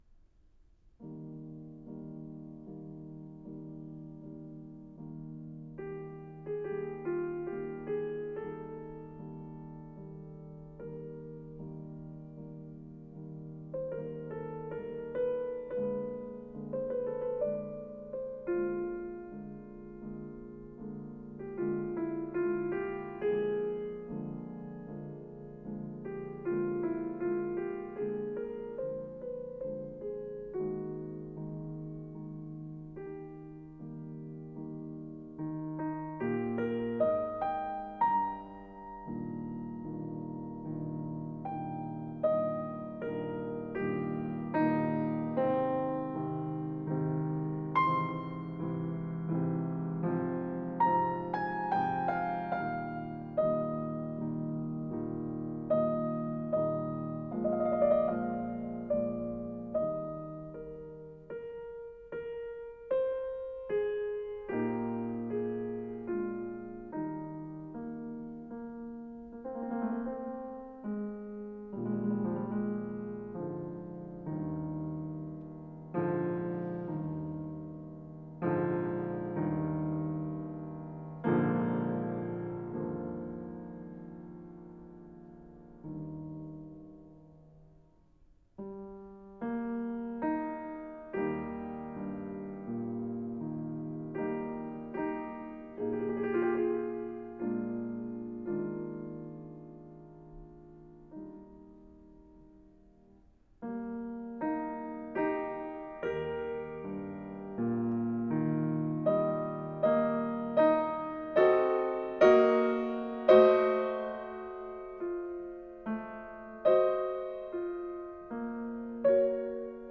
Beethoven. Piano Sonate Nr. 11 B-dur op. 22. 2 Adagio con molta espressione